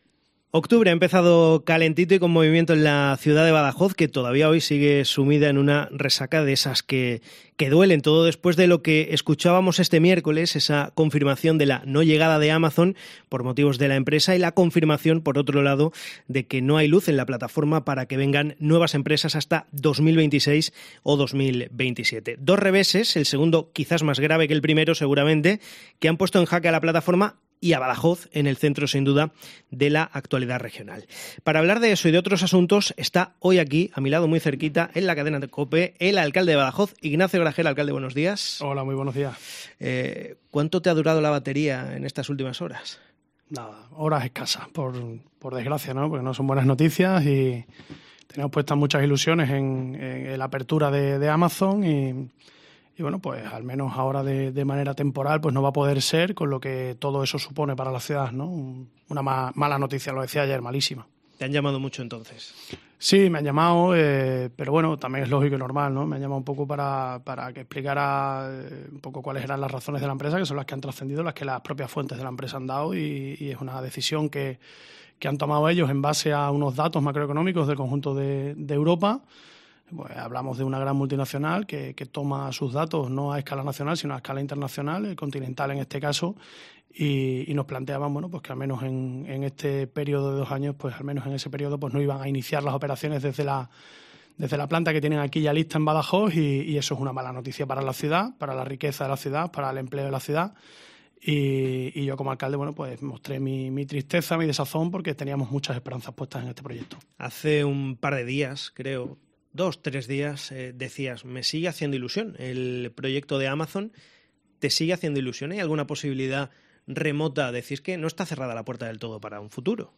Y la ha confirmado el alcalde de Badajoz, Ignacio Gragera, en la Cadena Cope: la capital pacense aspira a ser subsede del Mundial 2030 .